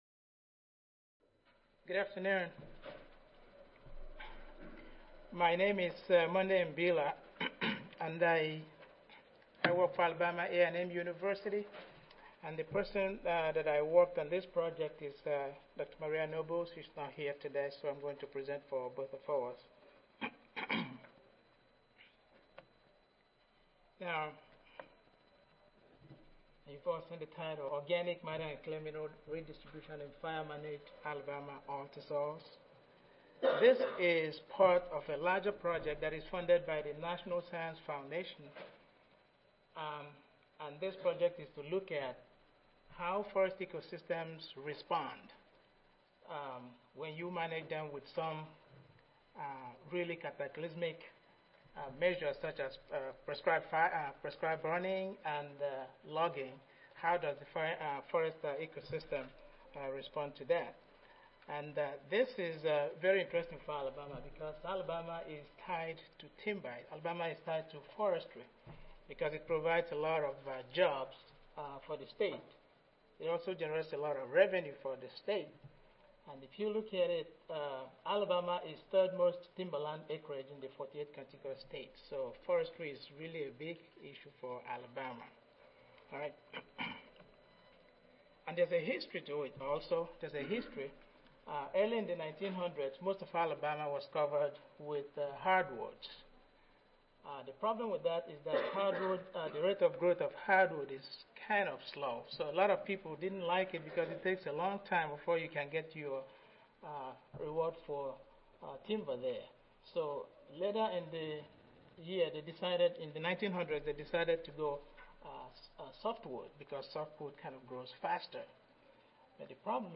Alabama A&M University Audio File Recorded Presentation